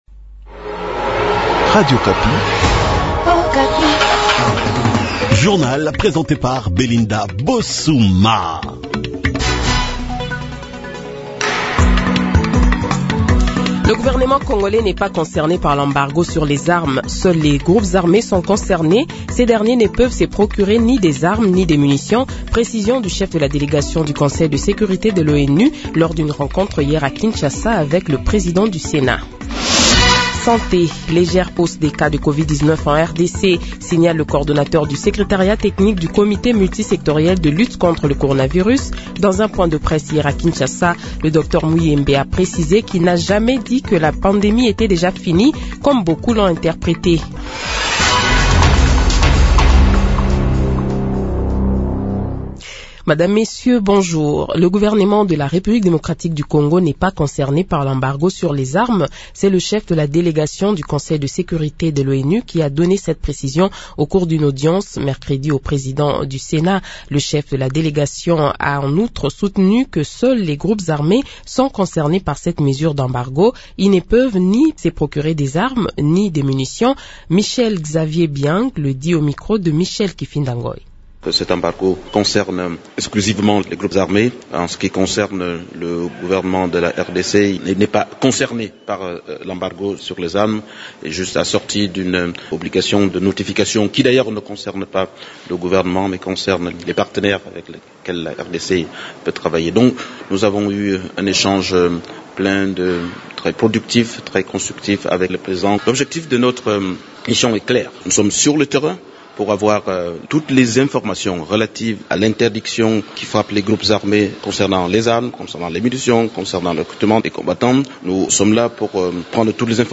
Journal Matin
Le Journal de 7h, 10 Novembre 2022 :